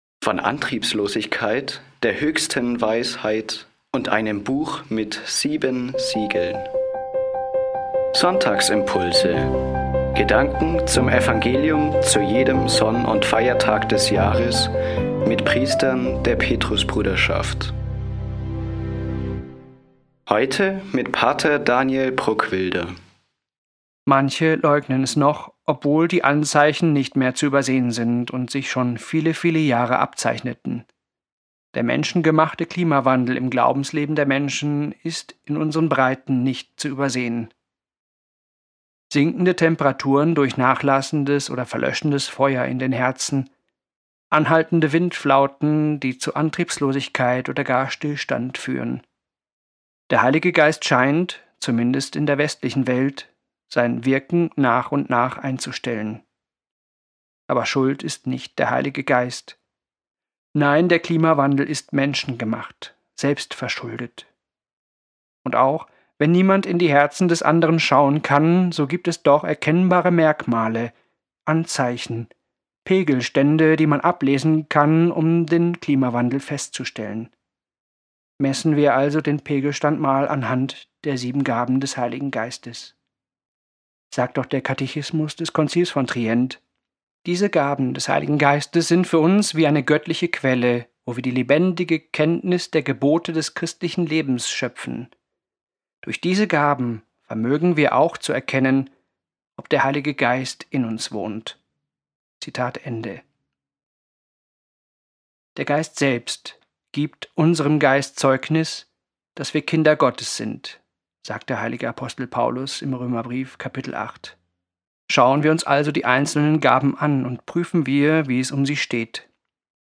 Gedanken zum Evangelium – für jeden Sonn- und Feiertag des Jahres mit Priestern der Petrusbruderschaft